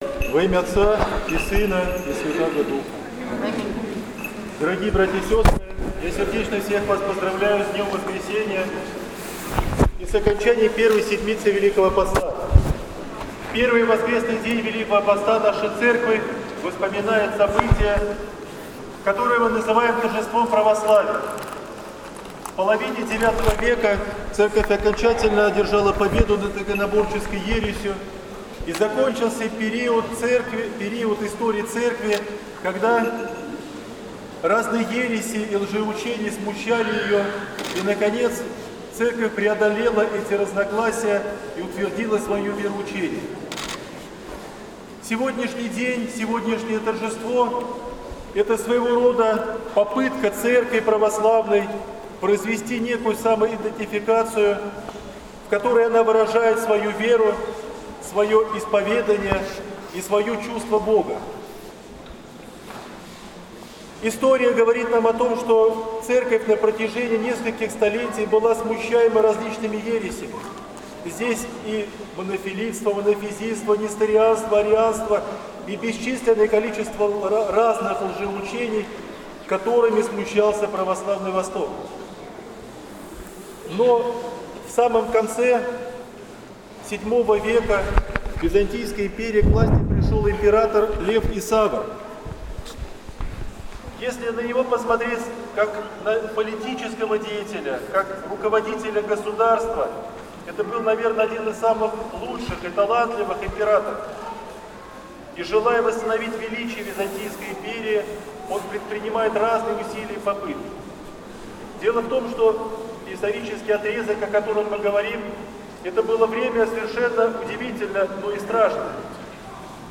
Проповедь Епископа Пахомия в Неделю Торжества Православия